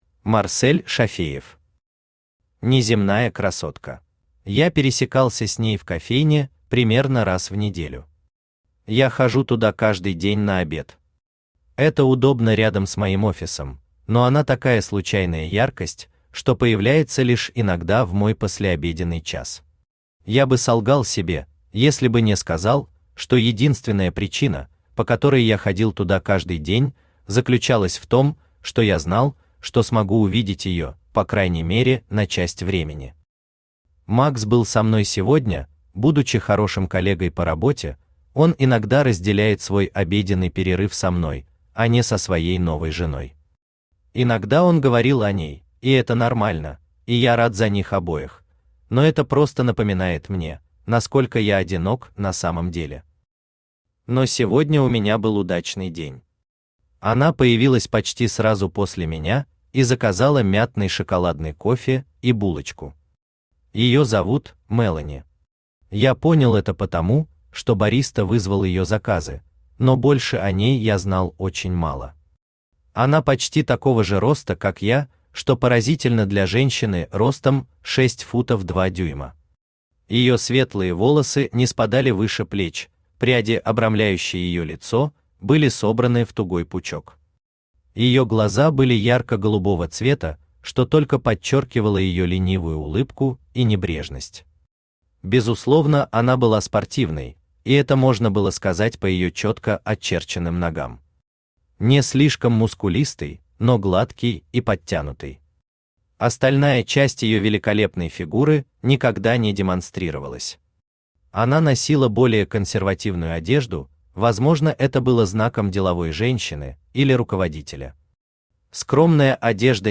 Аудиокнига Неземная красотка | Библиотека аудиокниг
Aудиокнига Неземная красотка Автор Марсель Зуфарович Шафеев Читает аудиокнигу Авточтец ЛитРес.